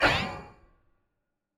Special & Powerup (27).wav